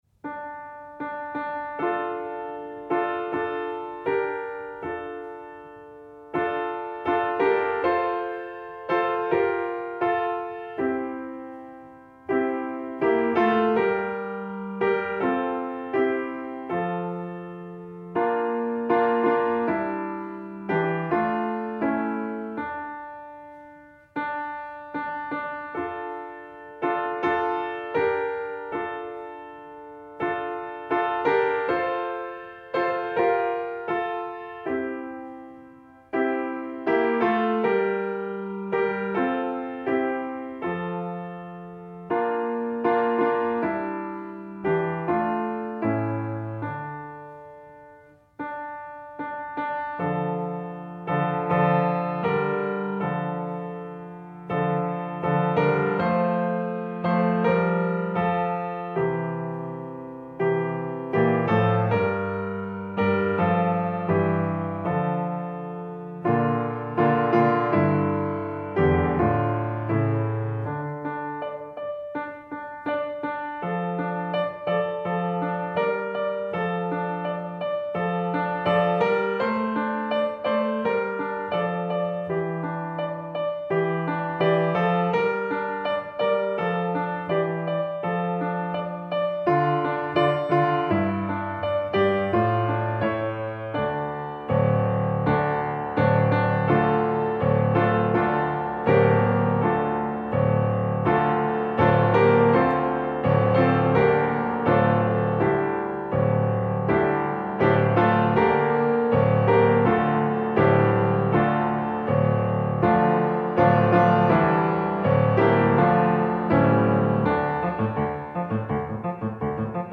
Chant Traditionnel
Genre :  Chanson
La mélodie est très simple et facile à apprendre.
Enregistrement piano
lesenfantsdepontoisepiano.mp3